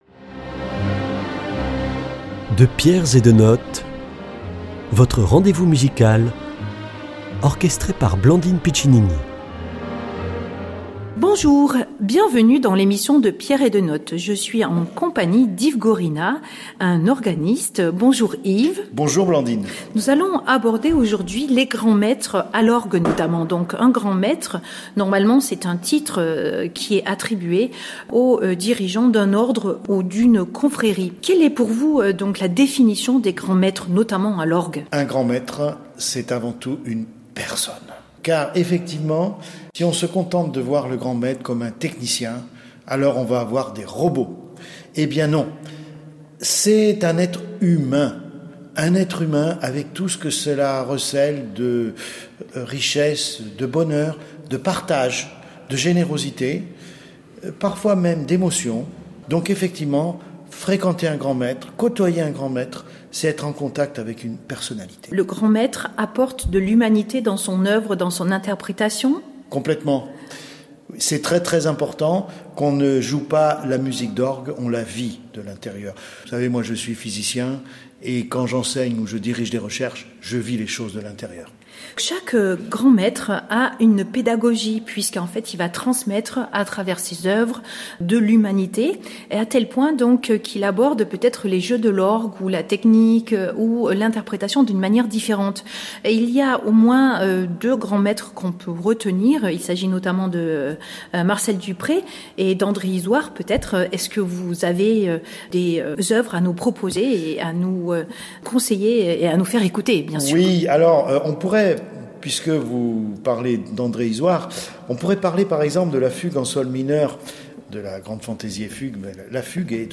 Les grands maîtres à l'orgue